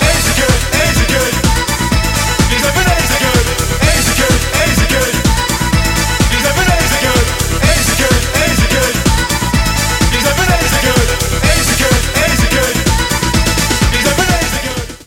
• Качество: 128, Stereo
электроника